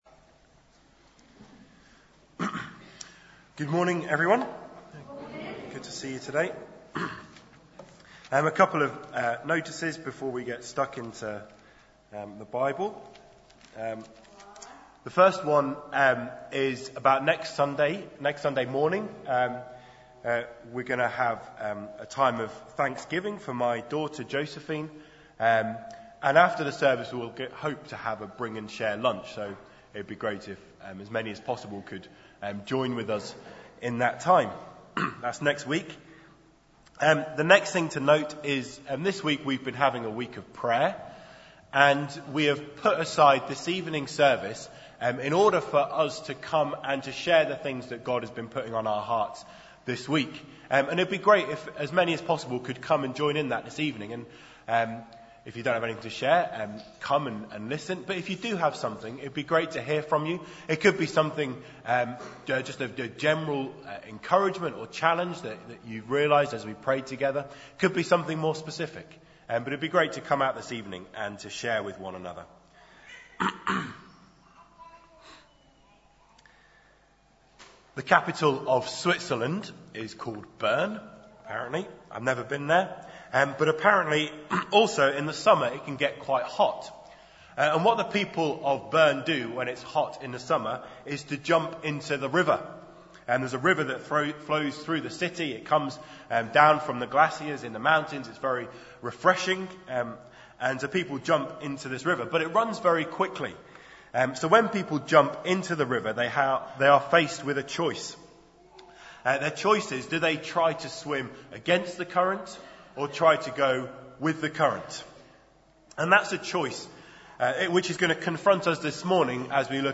speaks on Prayer For The Pilgrims 2 from John 17:20-26.